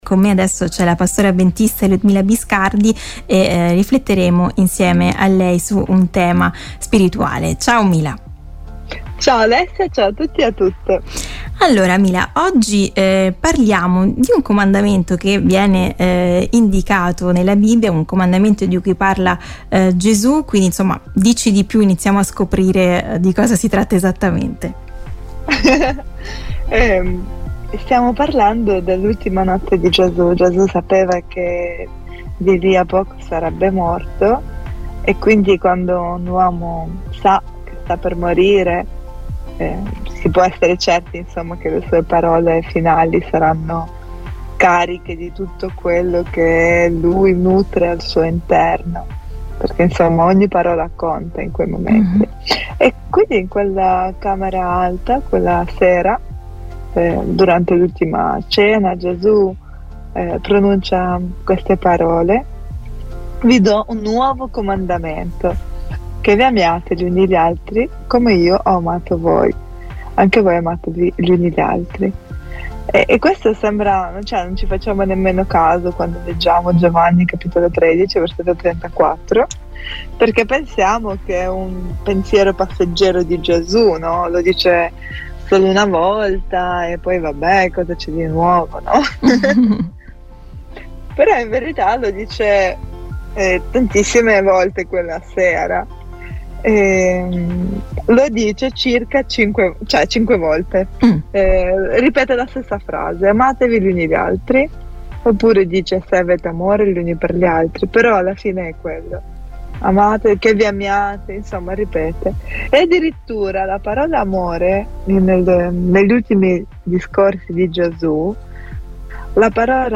ne ha parlato con la pastora avventista